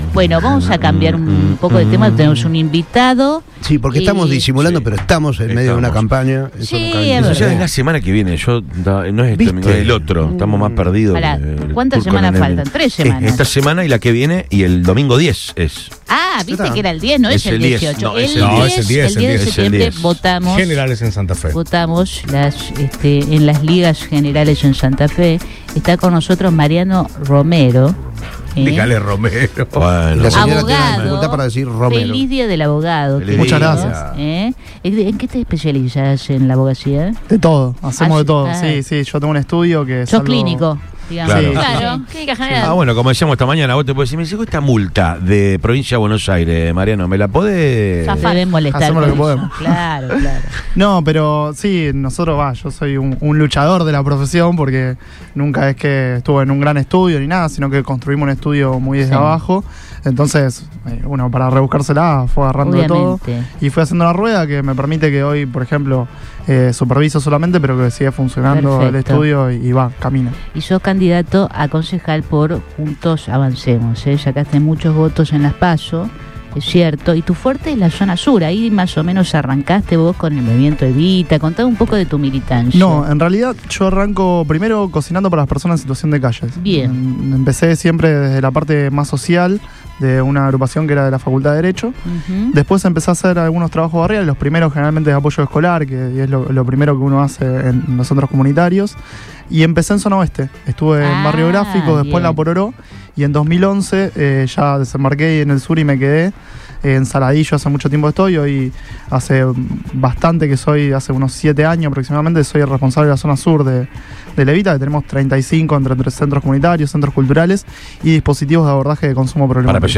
pasó por los estudios de Radio Boing donde dialogó con el equipo de Todo Pasa.